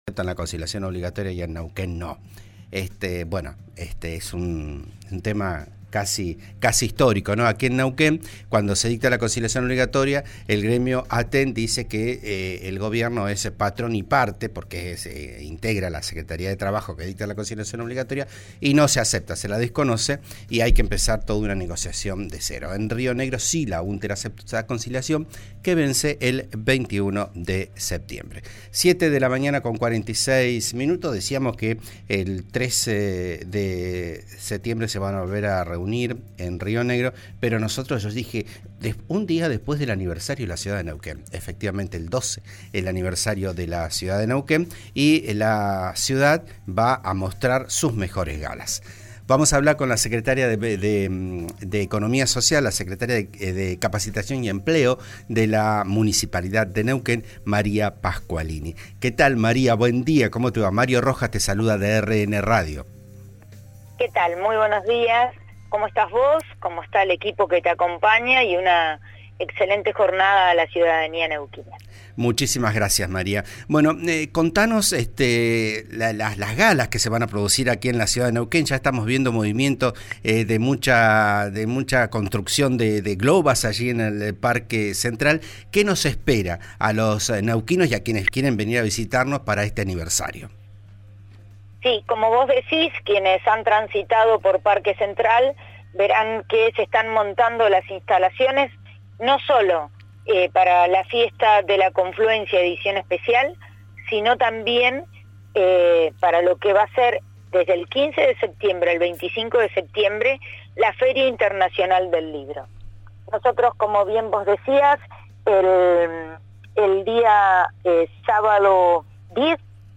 Escuchá la entrevista a la secretaria de Capacitación y Empleo de la Municipalidad de Neuquén, Maria Pasqualini, en «Vos a Diario» por RN RADIO: